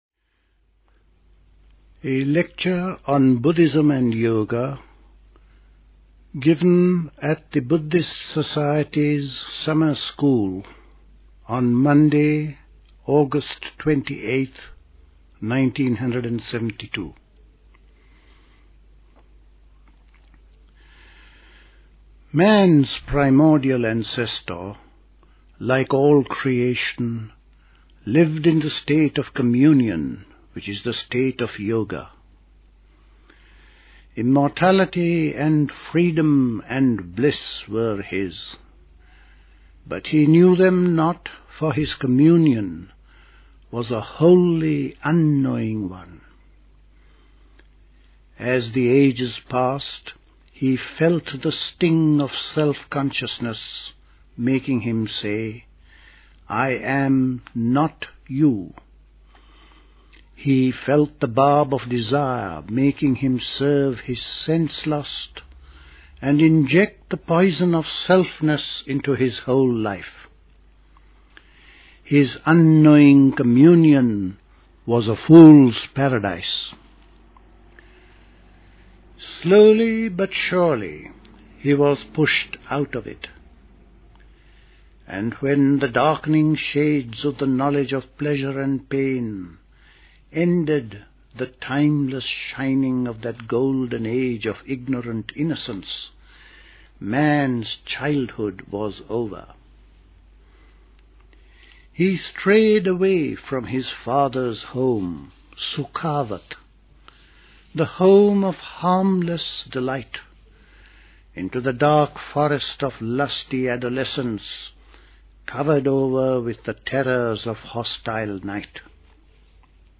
A talk
Recorded at the 1972 Buddhist Summer School.